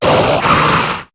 Cri de Cacturne dans Pokémon HOME.